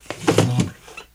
Chair Push Back Scrape Faster